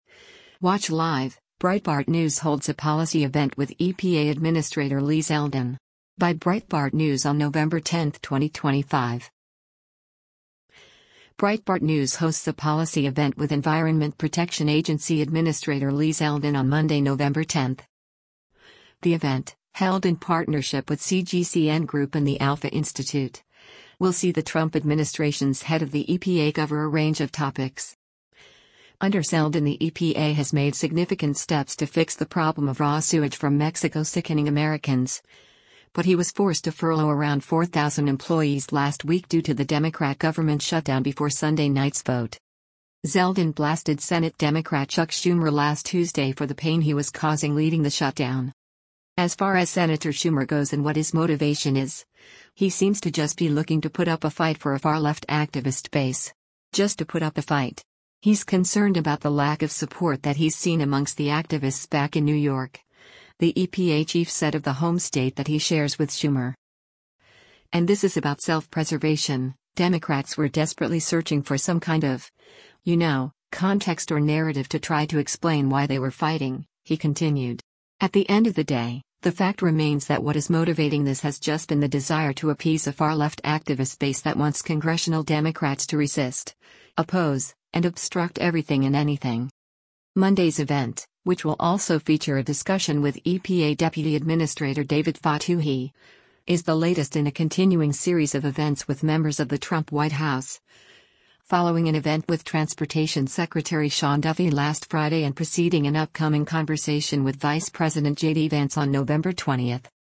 Breitbart News hosts a policy event with Environment Protection Agency Administrator Lee Zeldin on Monday, November 10.
Monday’s event, which will also feature a discussion with EPA Deputy Administrator David Fotouhi, is the latest in a continuing series of events with members of the Trump White House, following an event with Transportation Secretary Sean Duffy last Friday and preceding an upcoming conversation with Vice President JD Vance on November 20.